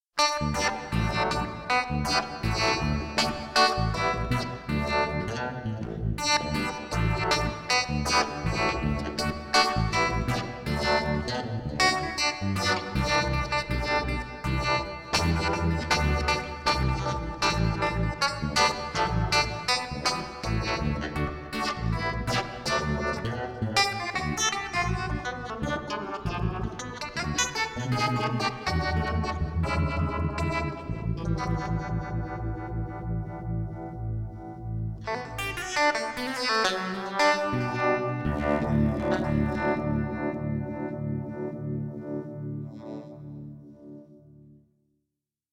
A DIY four stage phaser/vibrato (1 x 4 series/mono) with optional 2 x 2 parallel/
OldMacDonald had a farm(phaser).mp3